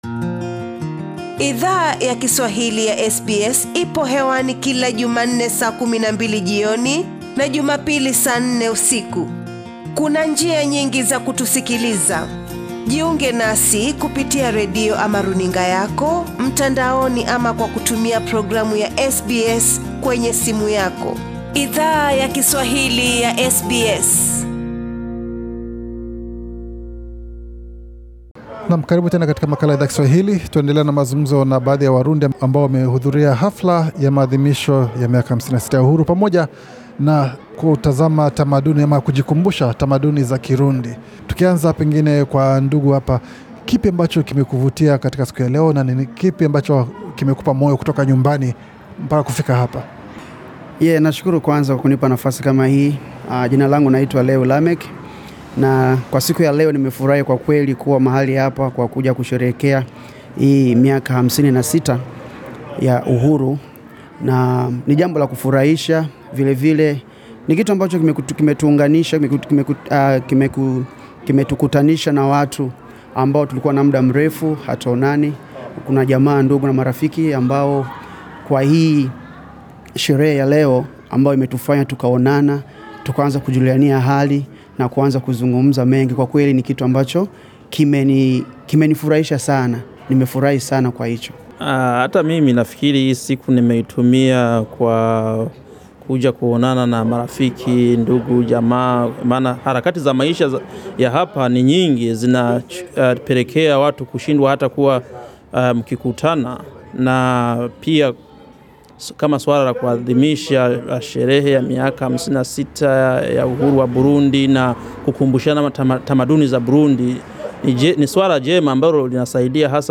Wanachama wa jamii yawarundi wanao ishi mjini Sydney, wachangia maoni yao kuhusu maadhimisho ya miaka 56 ya nchi yao ya asili pamoja na tamasha ya tamaduni yao Source: SBS Swahili